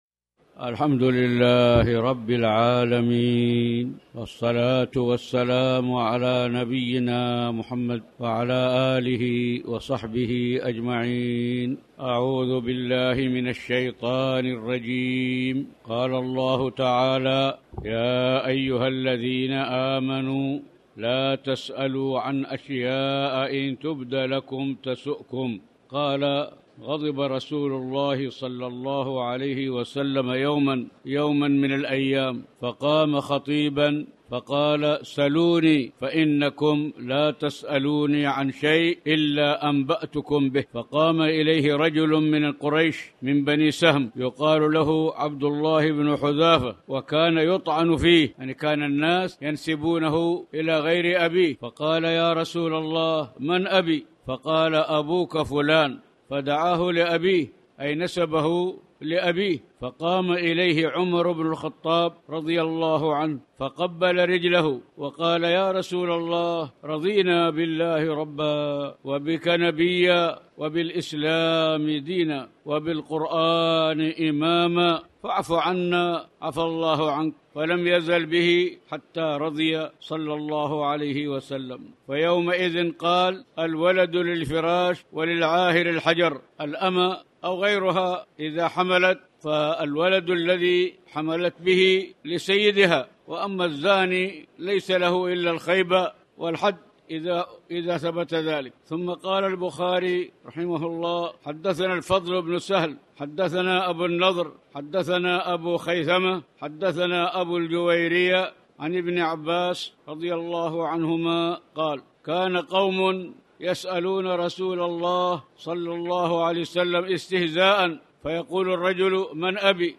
تاريخ النشر ١١ ربيع الثاني ١٤٣٩ هـ المكان: المسجد الحرام الشيخ